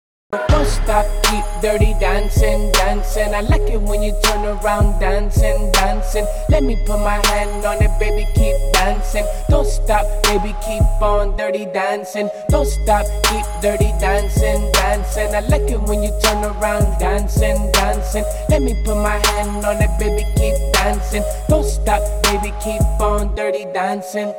спокойные
качающие
Rap